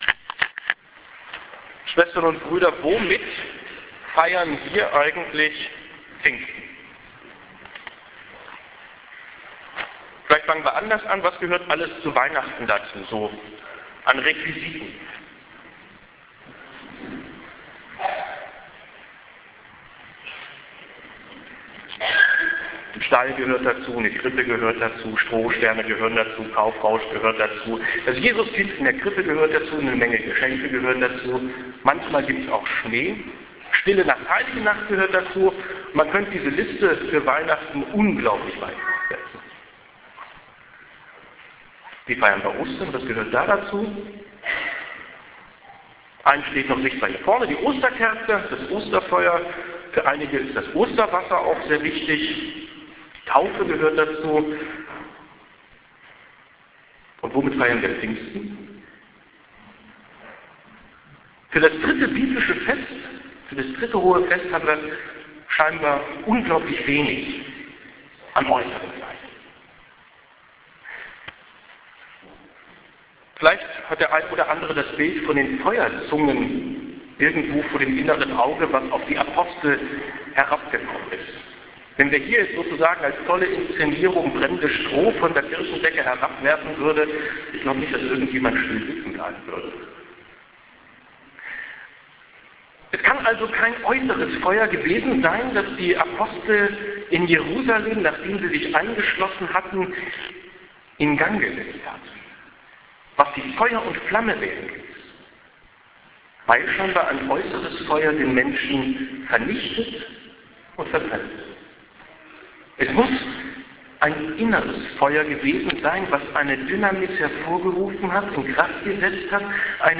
Einheit, Gemeinschaft, Heiliger Geist, Offenheit, Pfingsten, Predigten an Hochfesten
hier-klickt-die-predigt.mp3